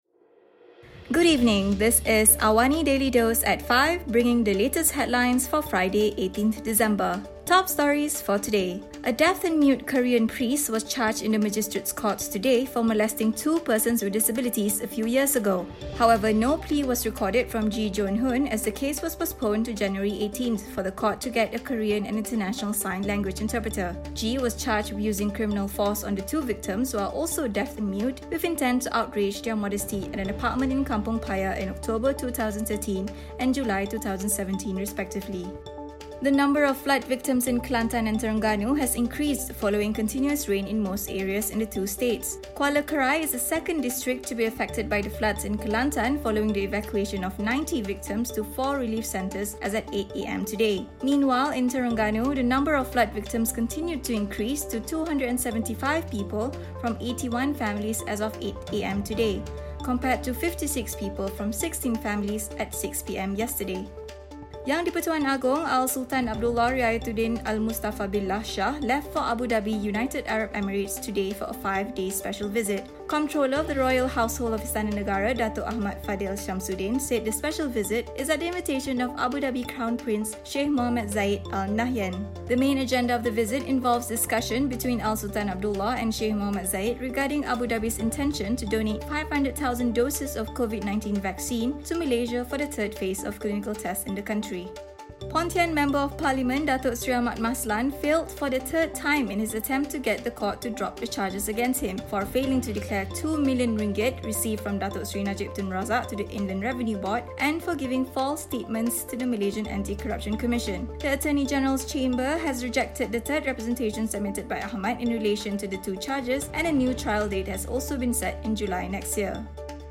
Also, Moderna's coronavirus vaccine is now expected to become the second vaccine allowed in a Western country, after a panel of US experts recommended emergency use approval. Listen to the top stories of the day, reporting from Astro AWANI newsroom — all in 3 minutes.